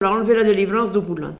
Mémoires et Patrimoines vivants - RaddO est une base de données d'archives iconographiques et sonores.
Localisation Saint-Hilaire-des-Loges
Catégorie Locution